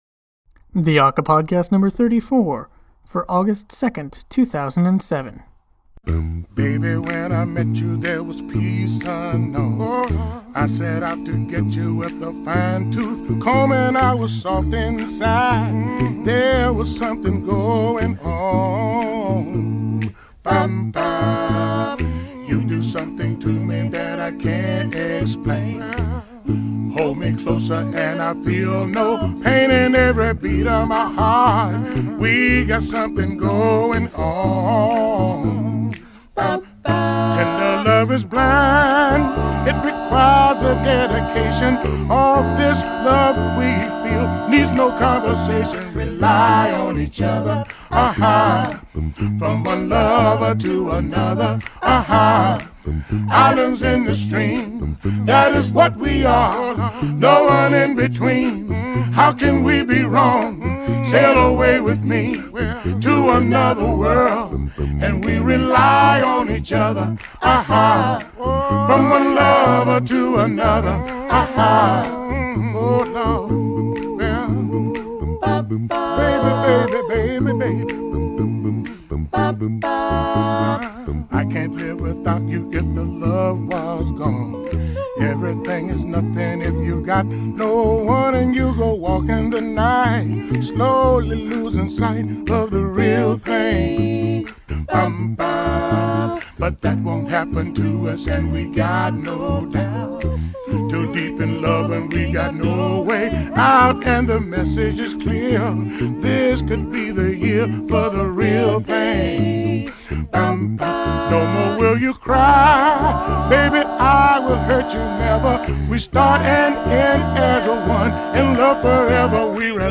He’s now working with a quartet called Talk of The Town, and they have a classic doo wop smoothness.